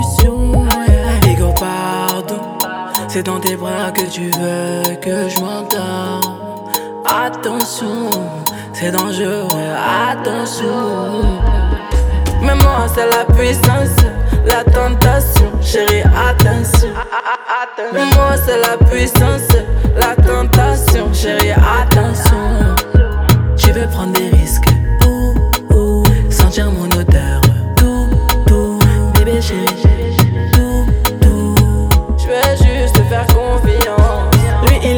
Жанр: Соул / R&b / Русские